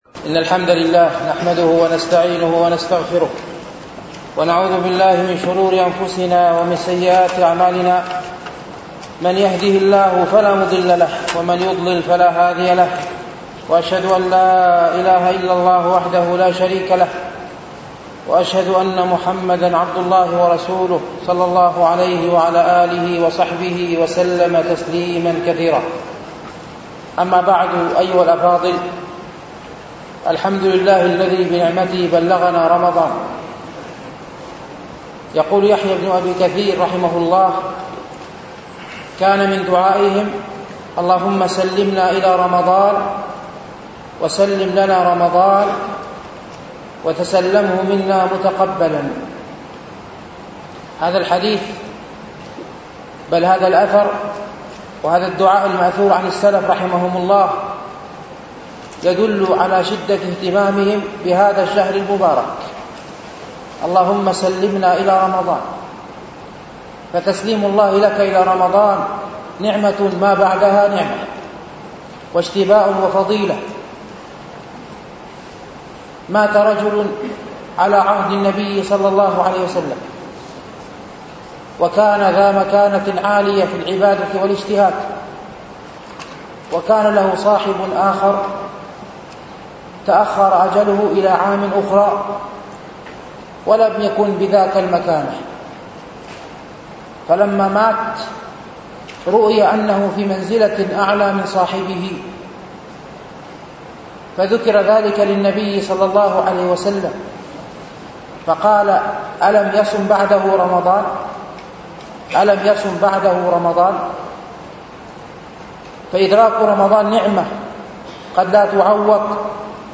شرح رياض الصالحين ـ الدرس الثالث والثمانون